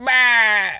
sheep_hurt_death_01.wav